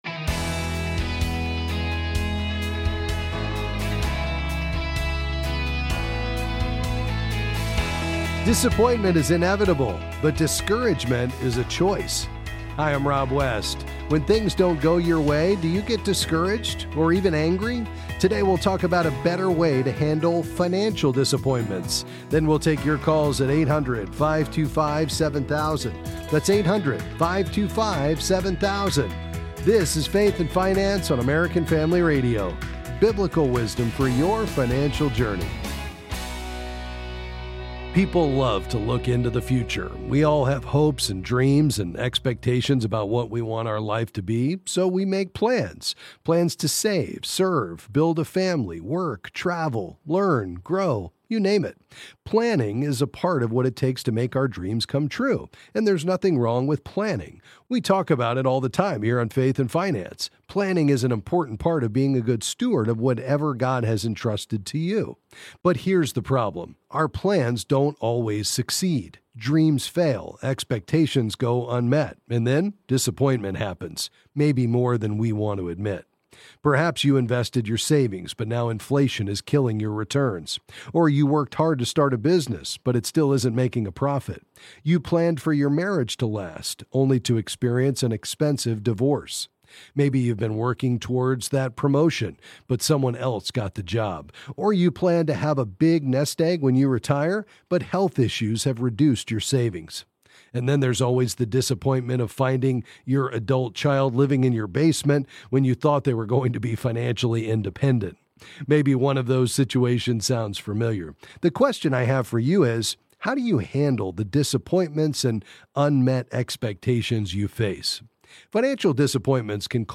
Then he answers listener calls and questions on a variety of financial topics.